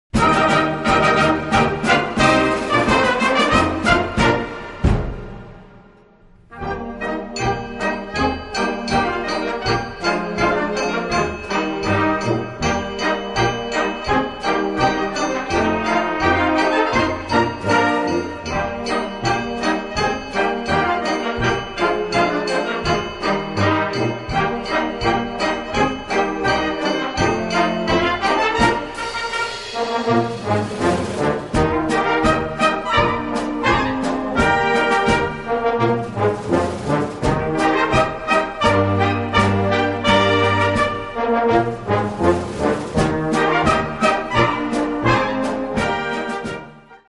Gattung: Konzertwalzer
Besetzung: Blasorchester